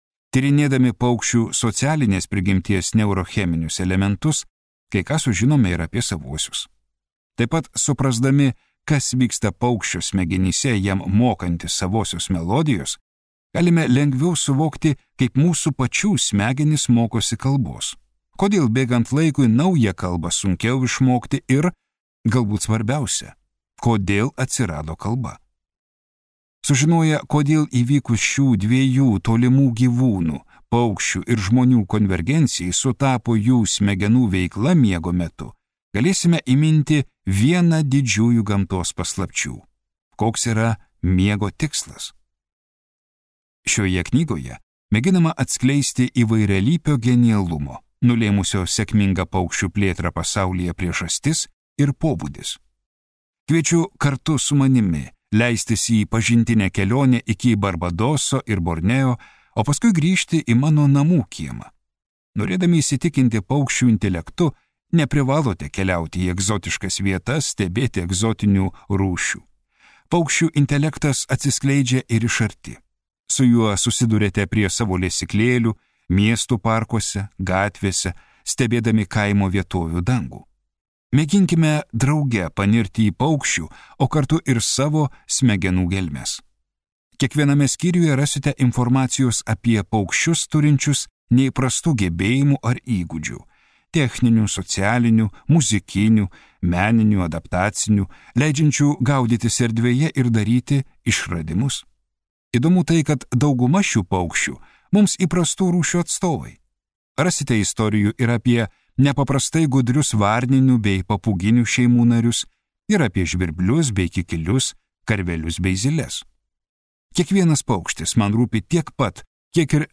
Genialieji paukščiai | Audioknygos | baltos lankos